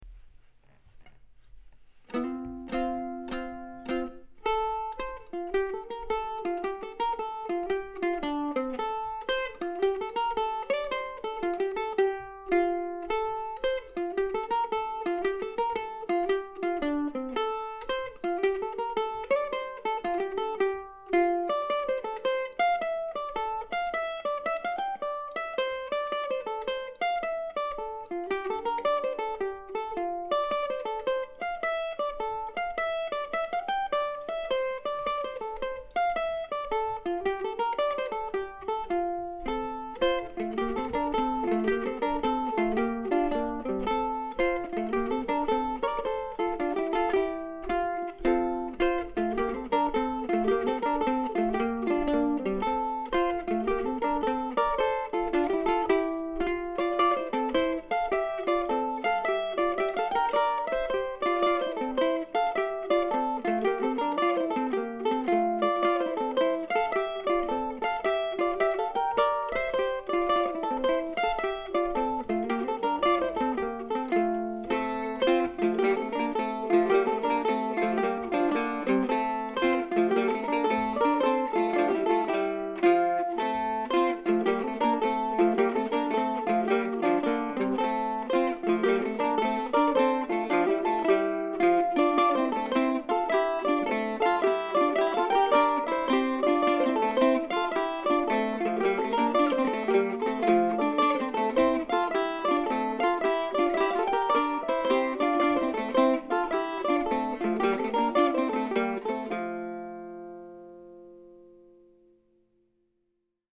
In any event, this prompted me to write a simple jig in F. Hope you enjoy it.